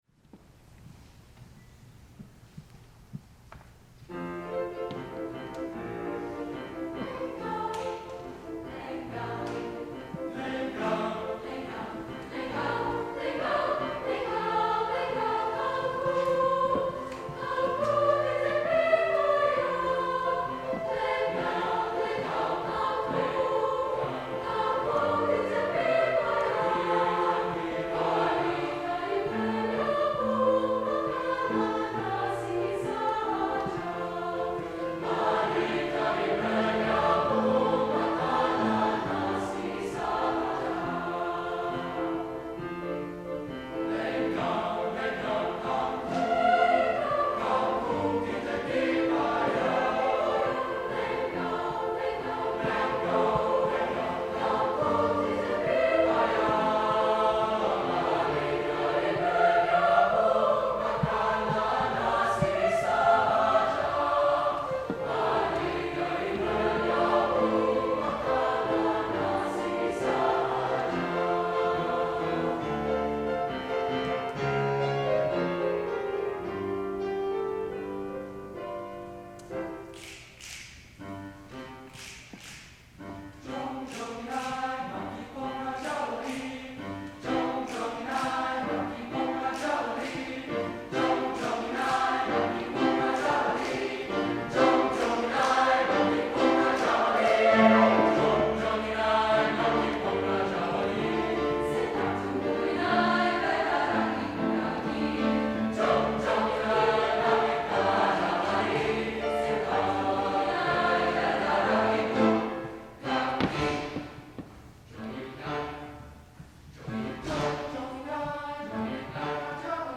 Choral Multicultural
SATB
Sample (Live)